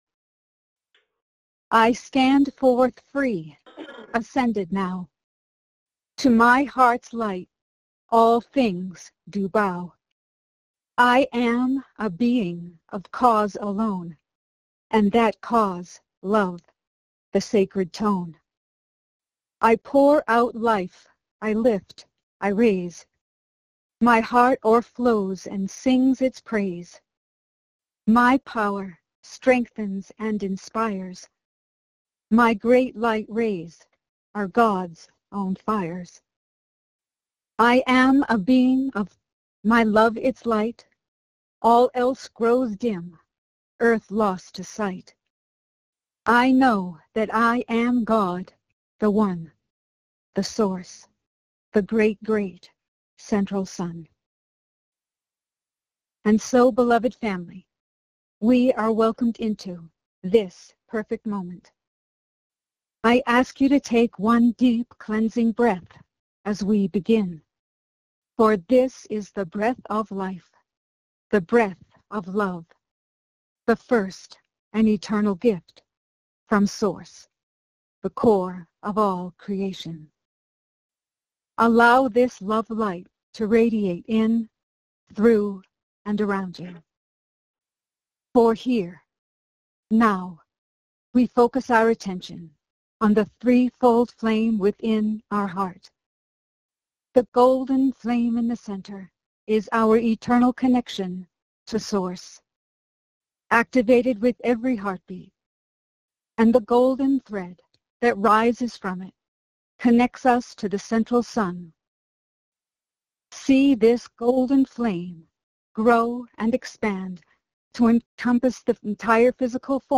Join master Yeshua and follow along in group meditation on this Eastern / Ascension day.